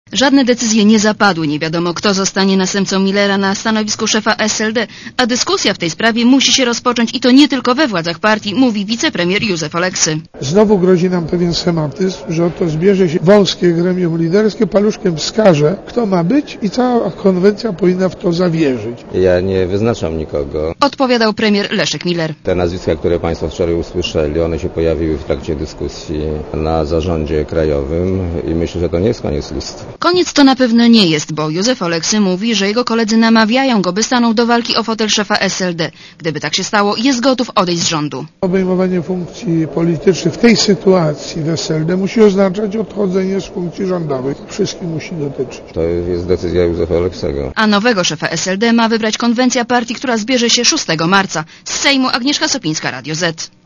Posłuchaj relacji reporterki Radia Zet (208 KB)